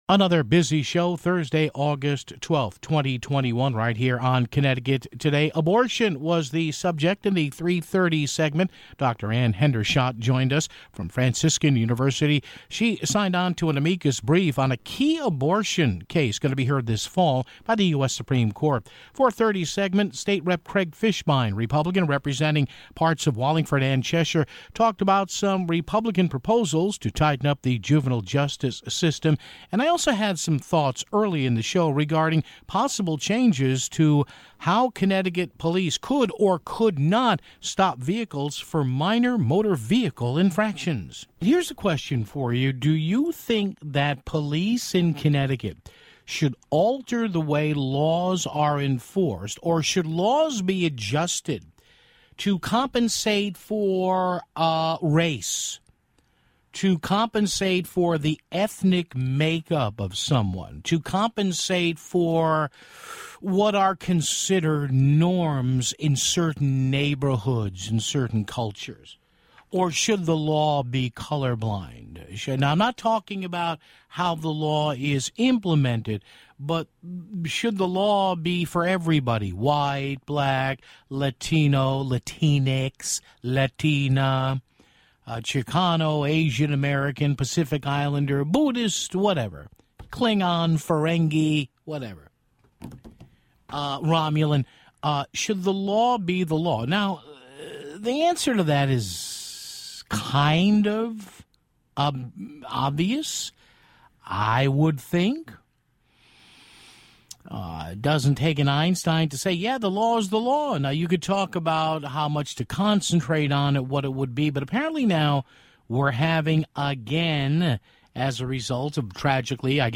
Finally, a return visit from Wallingford-Cheshire GOP State Representative Craig Fishbein to talk about juvenile justice reform proposals (33:08).